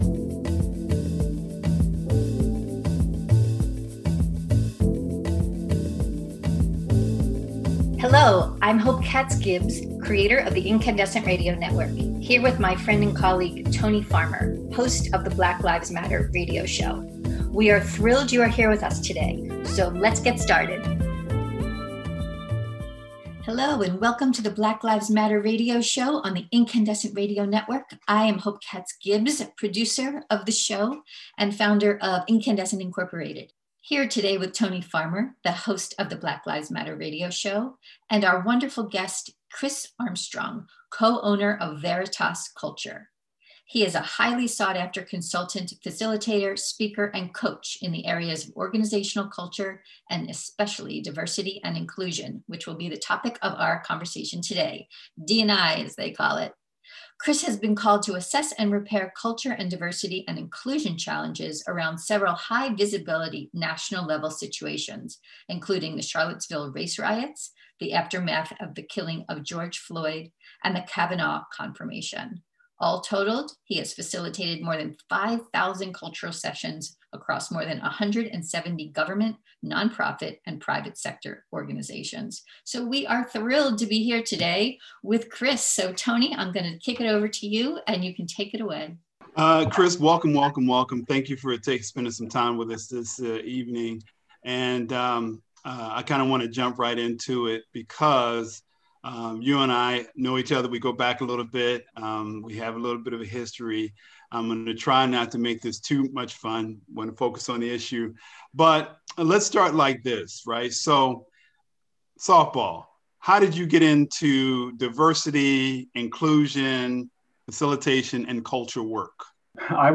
interview show